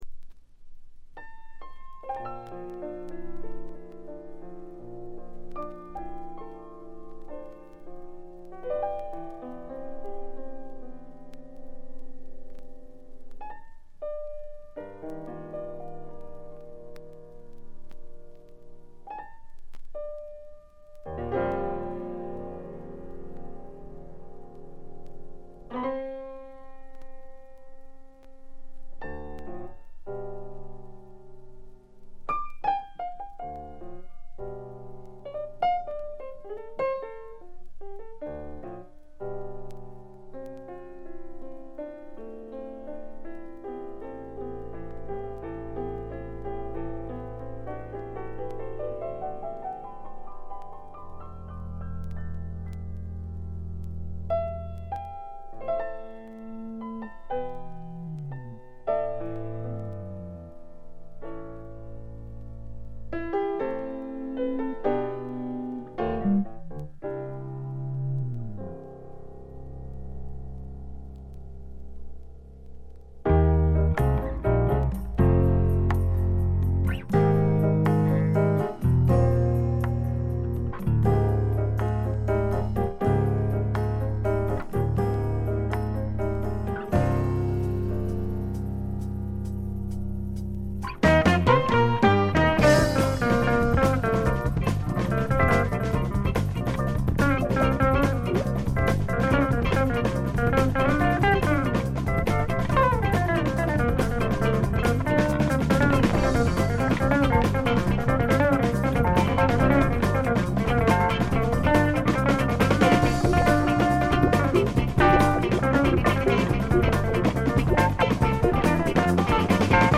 A面冒頭の静音部軽いチリプチ。
ファンキーでジャズっぽい面もあるサウンドが心地よくくせになります。
試聴曲は現品からの取り込み音源です。